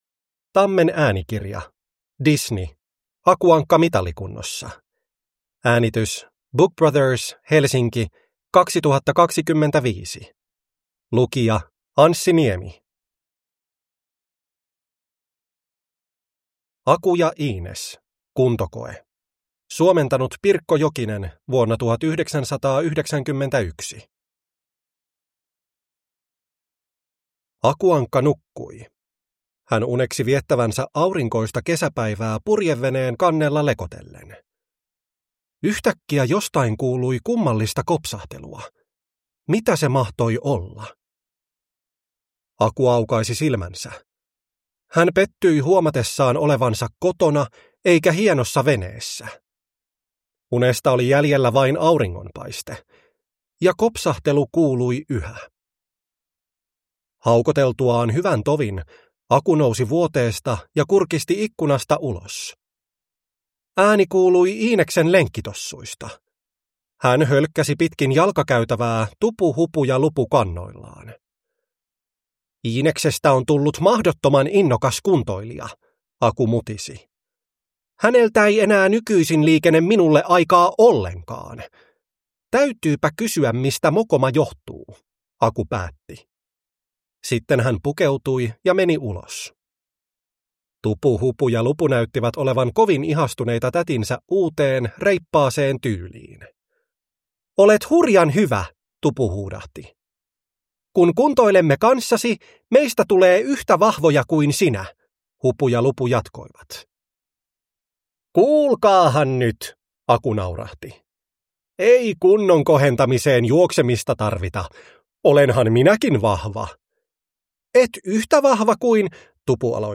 Aku Ankka mitalikunnossa – Ljudbok